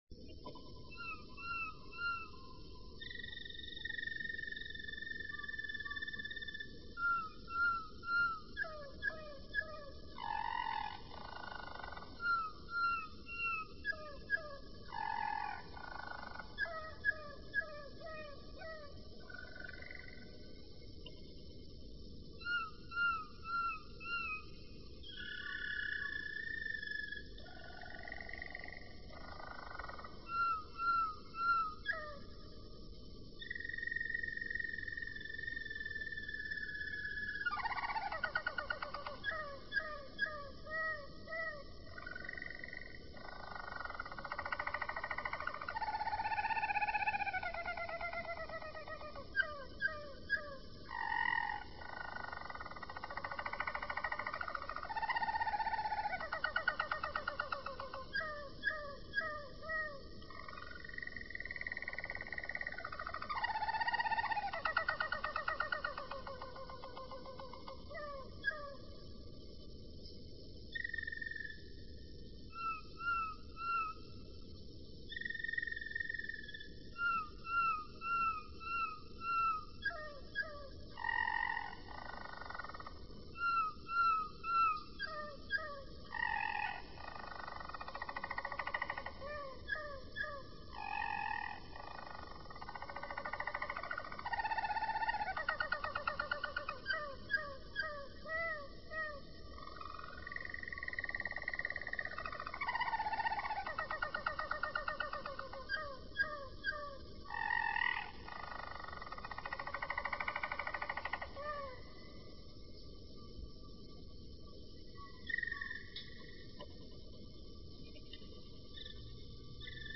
Suara Burung Kenari Roller Jerman
3. Suara Kenari Roller Jerman 3